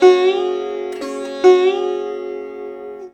SITAR LINE49.wav